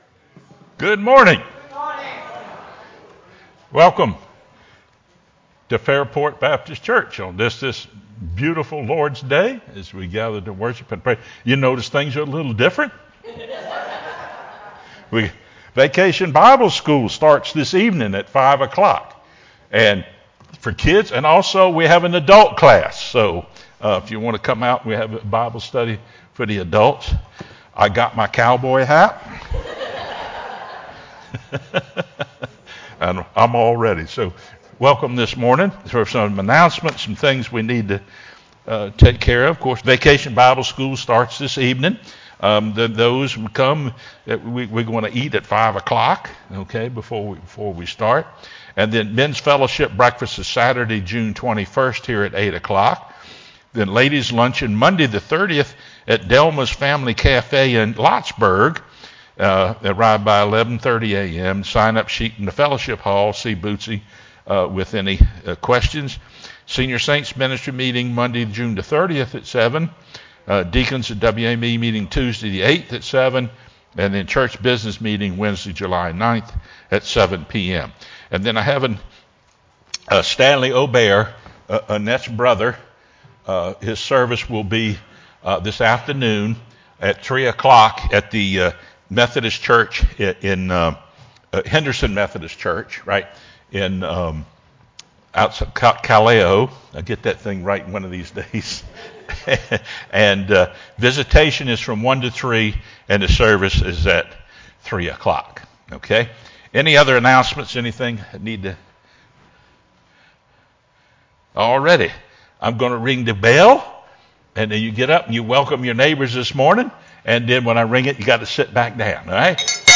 sermonJun08-CD.mp3